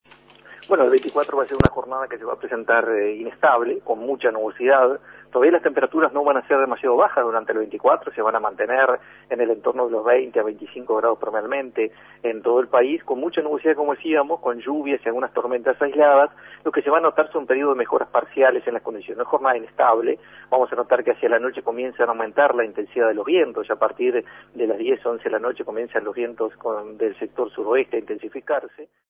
Pronóstico para el 24 de agosto